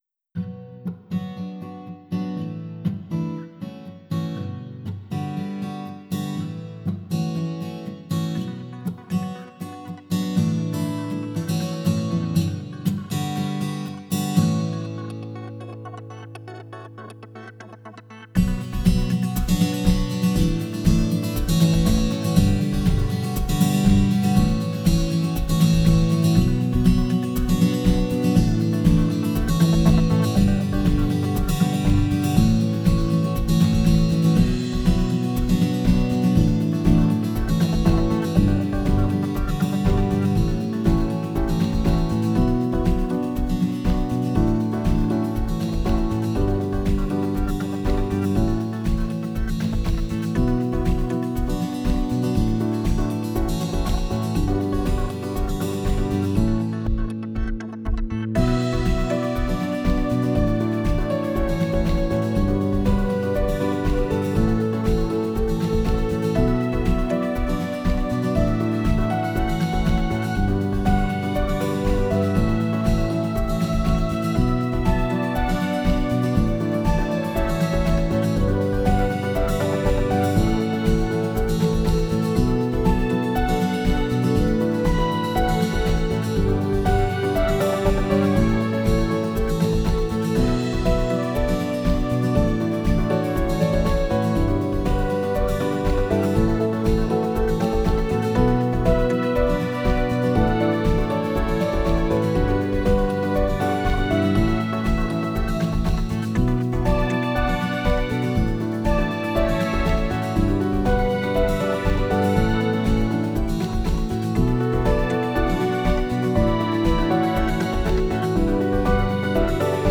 Tags: Piano, Strings, Guitar, Percussion, Digital